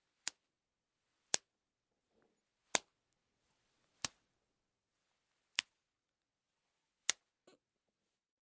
Destruction, impact, crush » 16 slap
描述：slap
声道立体声